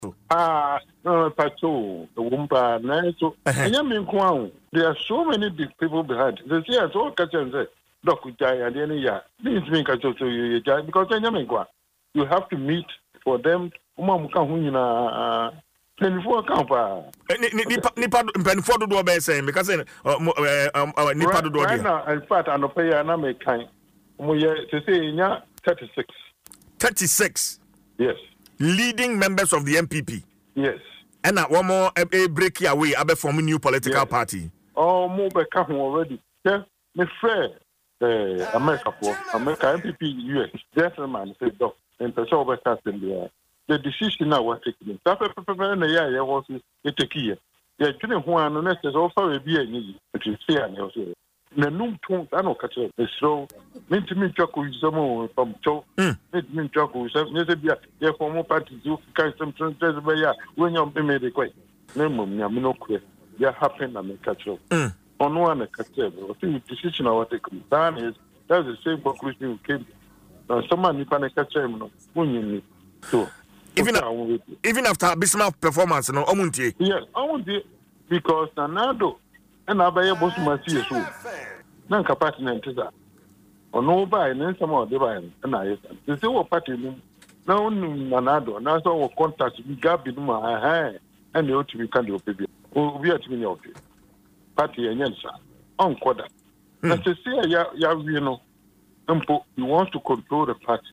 an interview on Adom FMs morning show Dwaso Nsem.